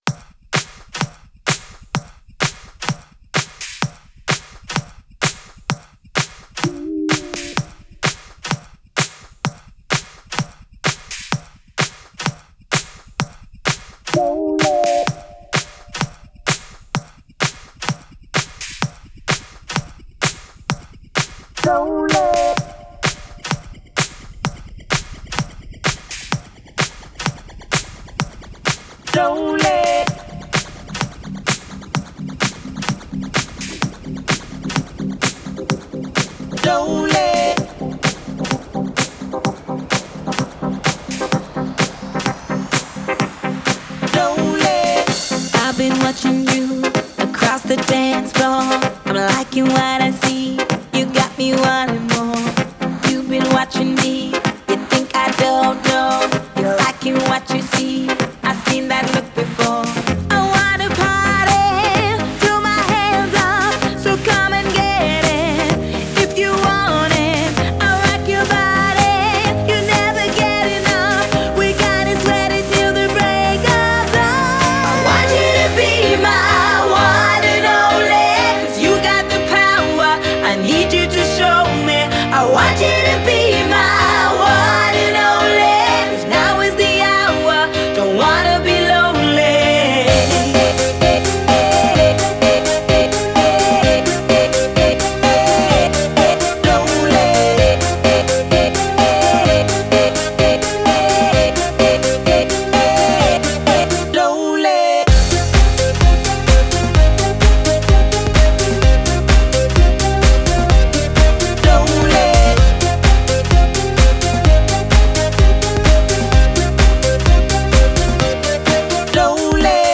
egy igazi könnyed pop sláger